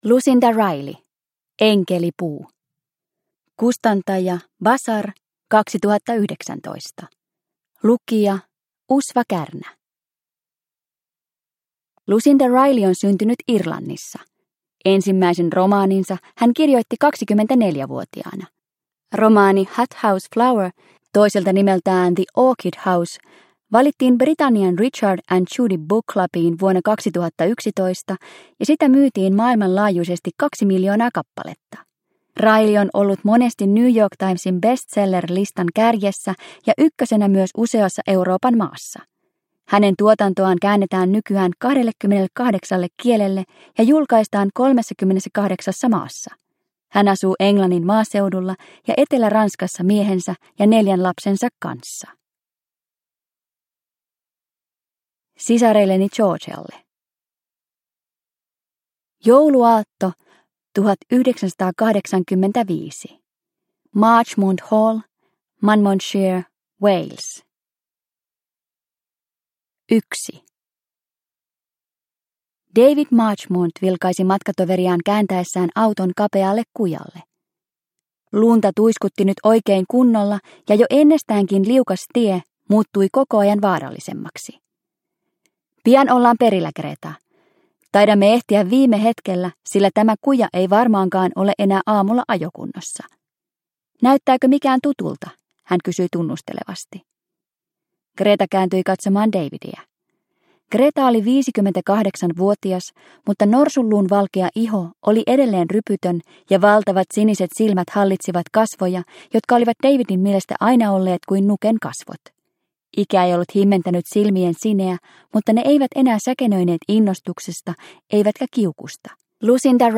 Enkelipuu – Ljudbok – Laddas ner